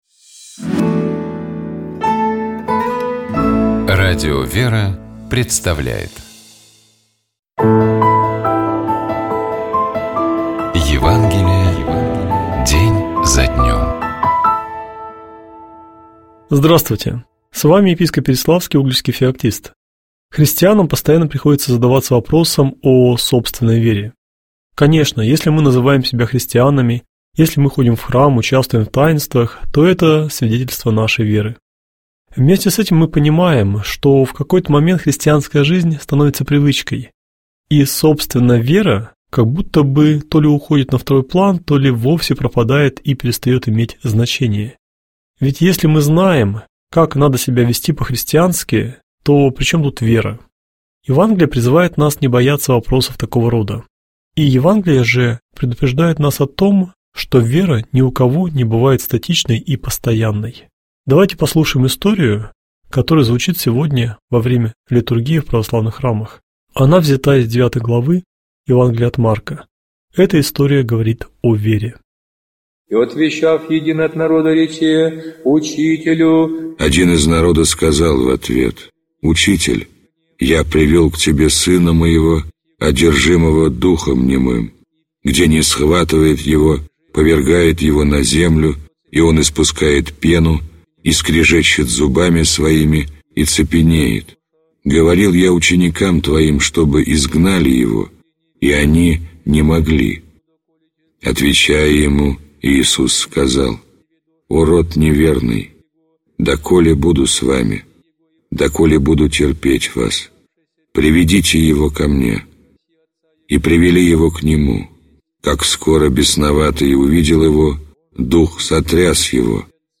Читает и комментирует протоиерей